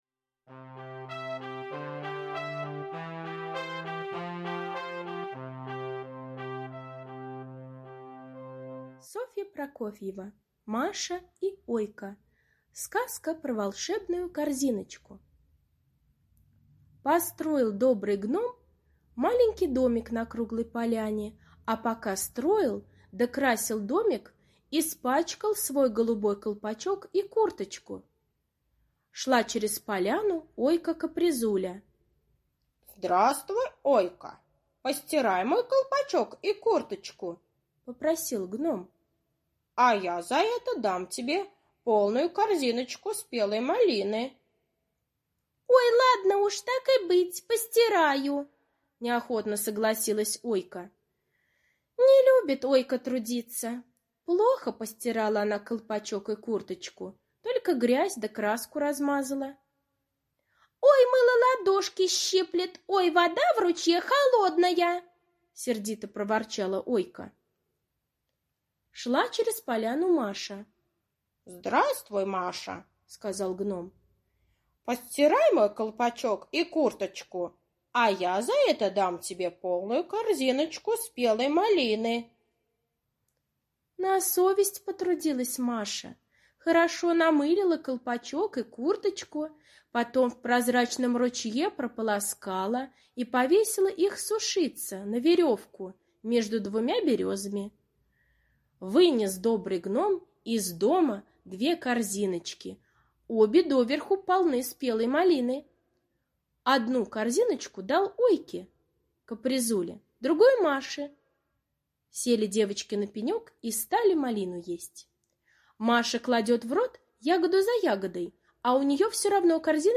Сказка про волшебную корзиночку - аудиосказка Прокофьевой С. Сказка о том, как Маша и Ойка помогали Гному стирать его колпачок и курточку.